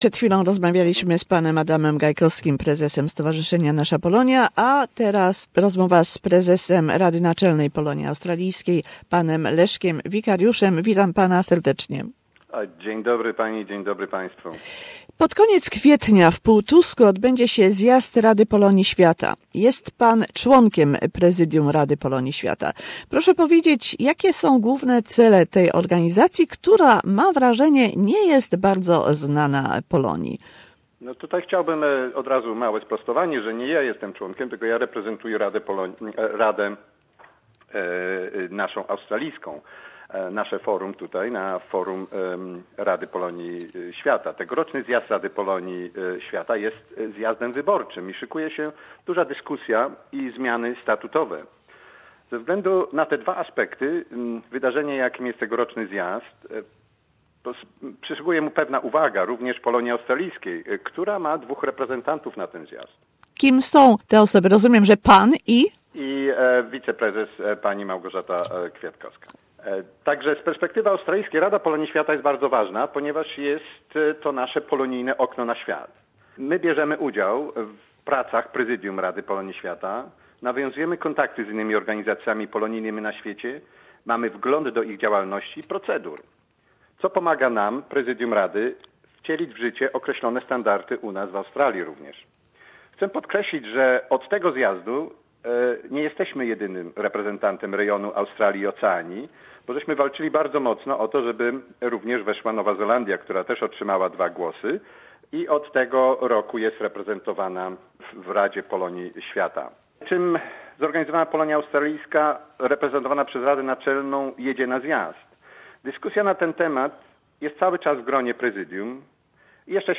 Two interviews